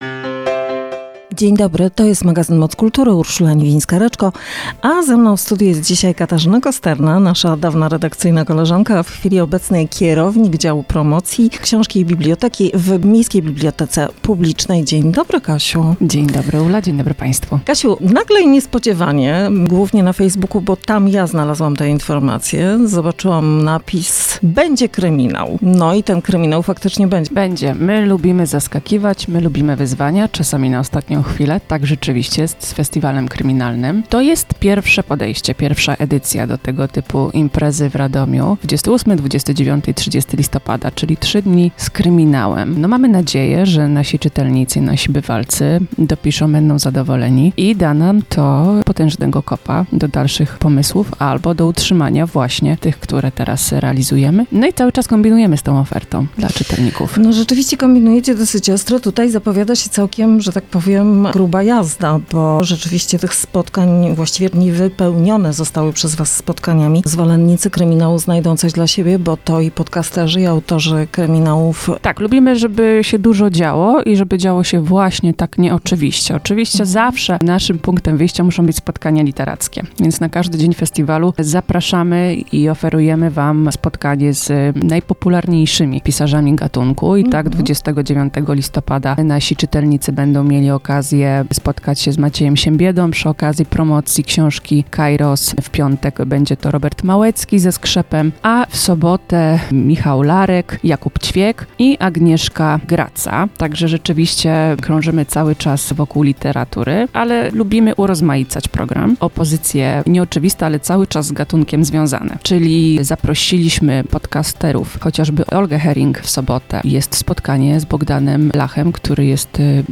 Panie rozmawiają o rozpoczynającym się jutro festiwalu „Będzie Kryminał!”